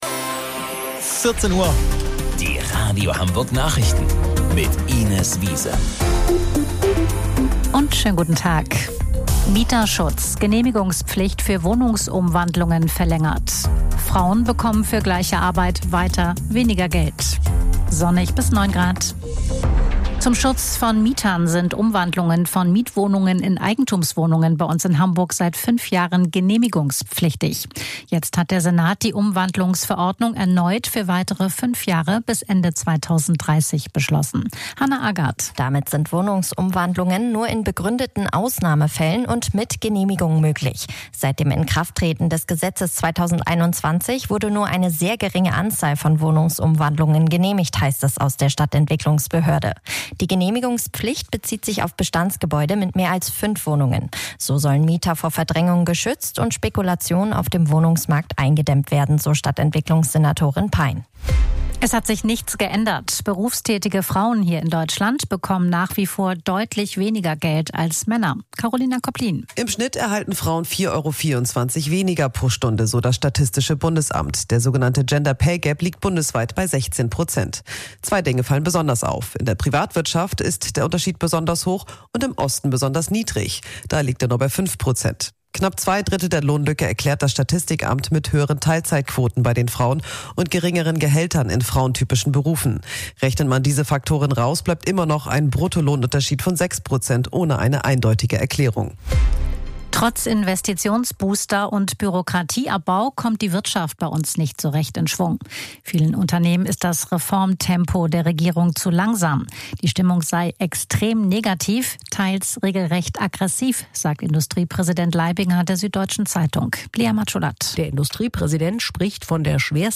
Radio Hamburg Nachrichten vom 16.12.2025 um 14 Uhr